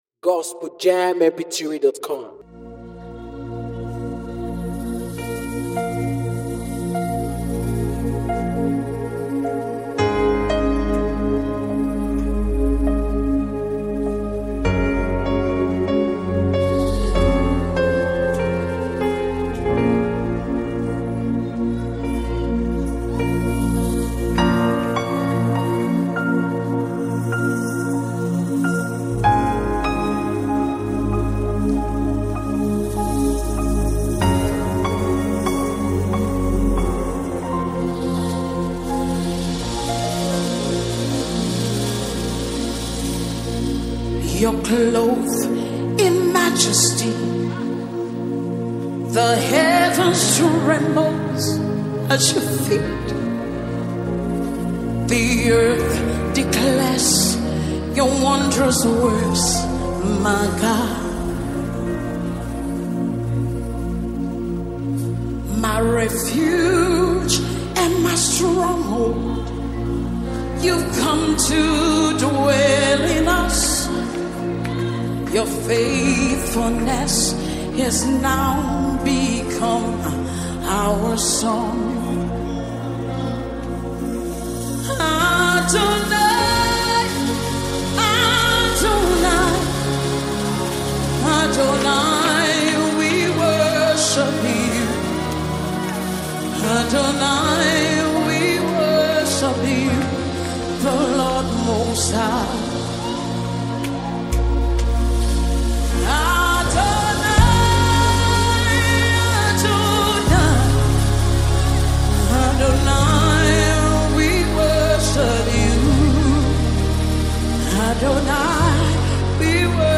powerful worship song
With rich worship lyrics and a reverent melody